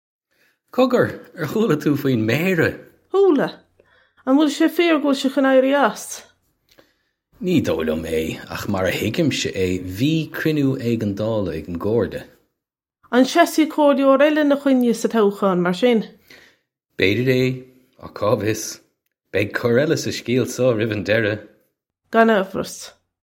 This comes straight from our Bitesize Irish online course of Bitesize lessons.